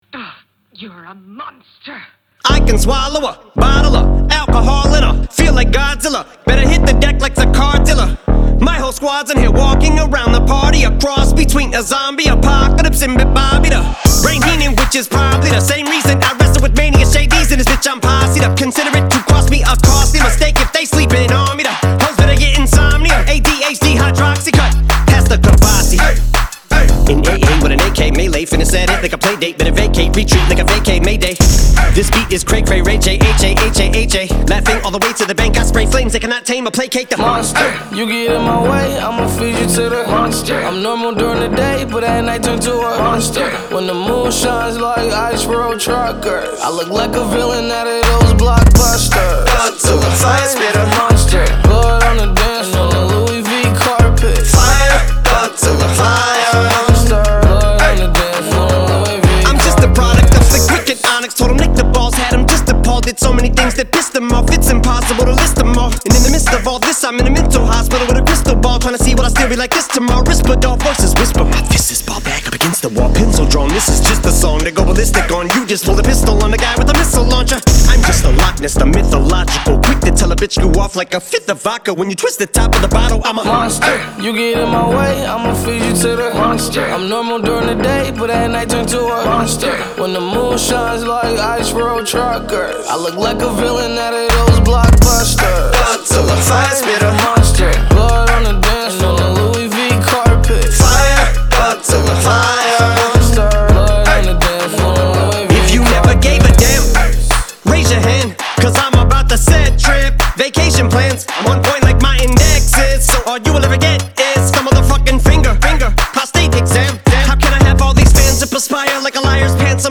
2025-04-08 20:26:59 Gênero: Rap Views